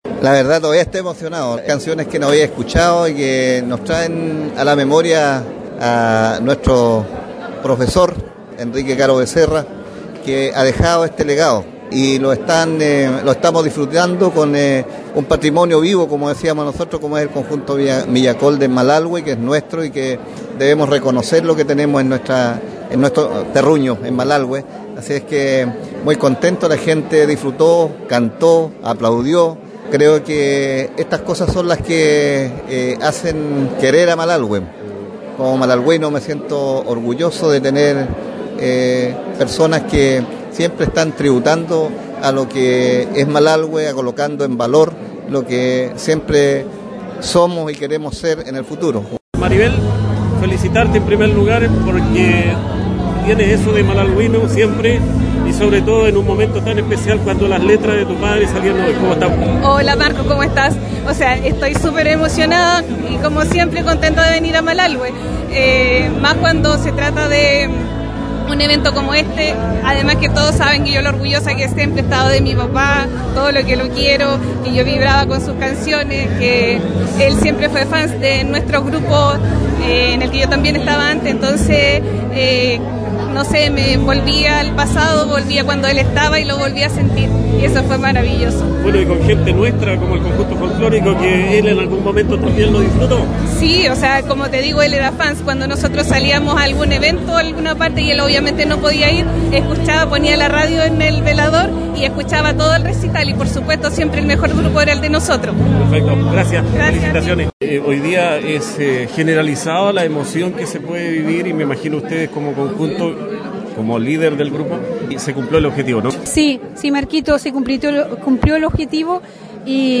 En el salón auditorio de la Escuela Padre Carlos, el Conjunto de Proyección Folclórica Millacol ofreció un emotivo recital de temas inéditos, como parte del proyecto «Fortaleciendo la identidad a través del canto, con temas inéditos», financiado por el Gobierno Regional de Los Ríos y su Consejo Regional.